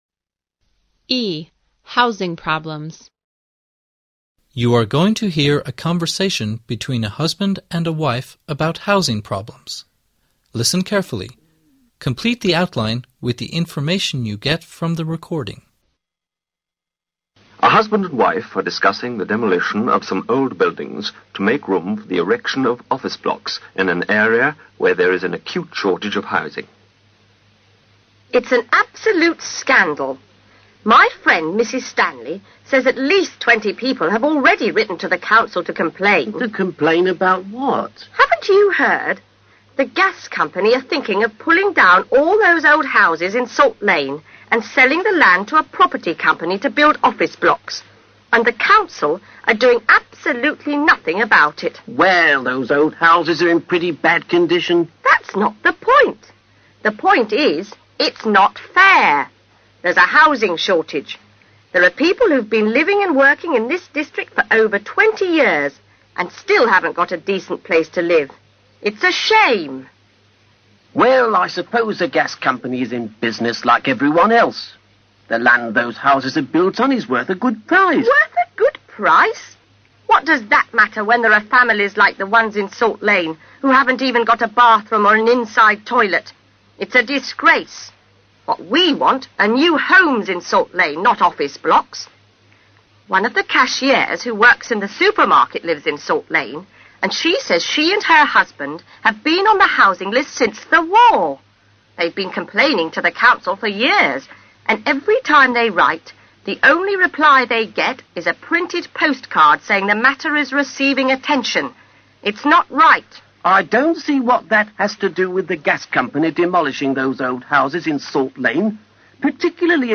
You're going to hear a conversation between husband and wife about hosing problems, listen carefully, complete the outline with the information you get from the recording.
A husband and a wife are discussing the demolition of some old buildings to make room for the erection of office blocks in an area where there is an acute shortage of housing.